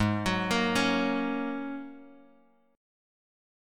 Ab9sus4 Chord
Listen to Ab9sus4 strummed